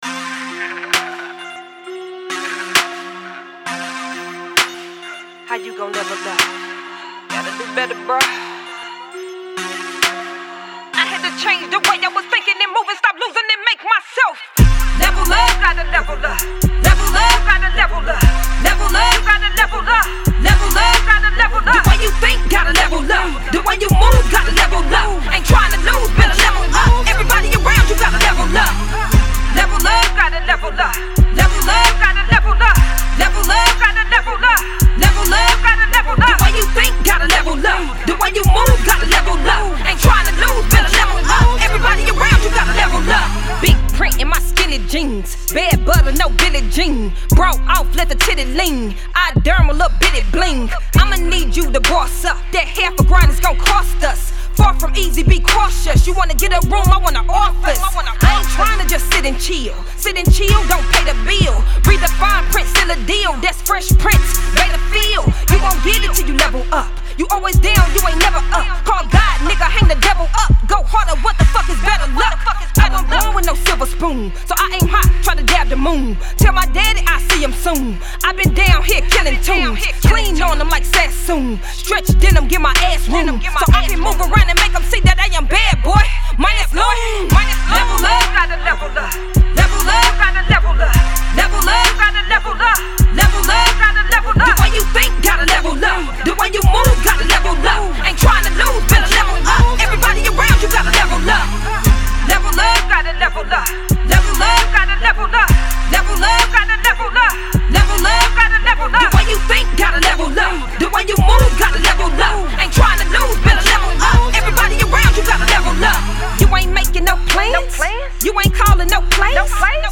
Hiphop
Hard Bass, solid bars, and a Live delivery